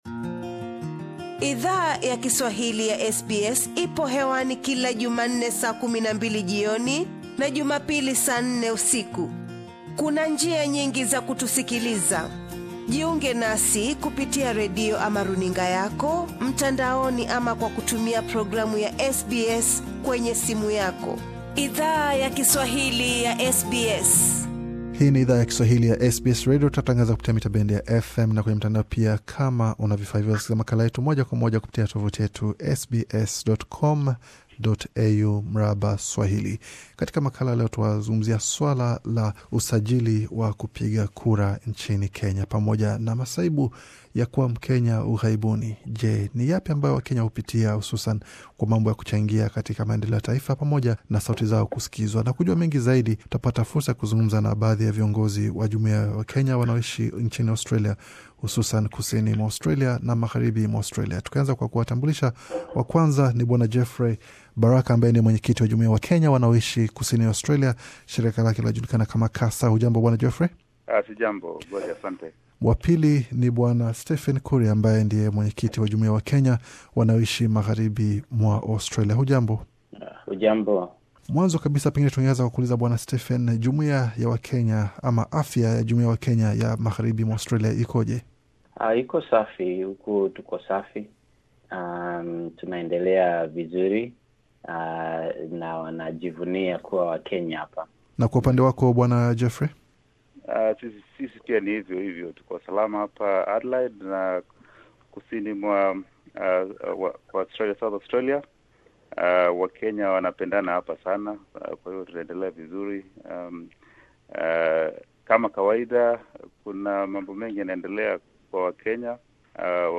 SBS Swahili ilizungmza na viongozi wa jamii yawa Kenya wanao ishi Kusini na Magharibi Australia, kuhusu mchakato wa usajili wa wapiga kura nchini Kenya.